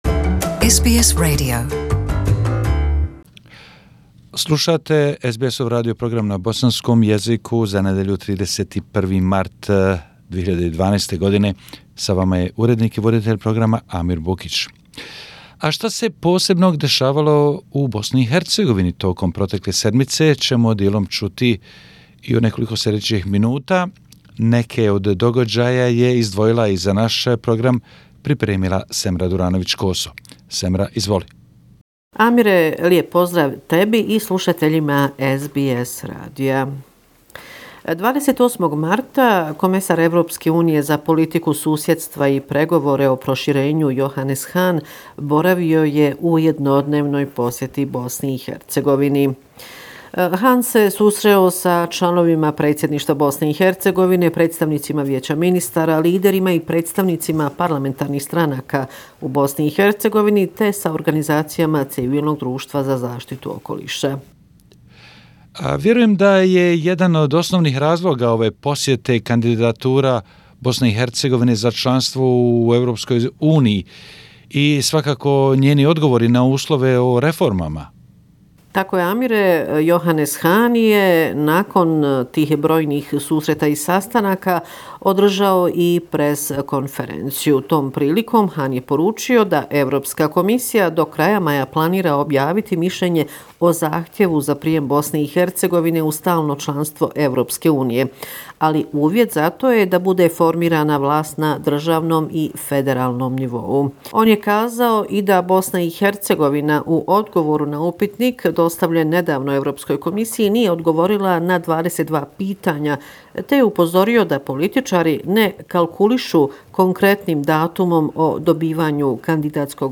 Bosnia and Herzegovina, weekly report, March 31, 2019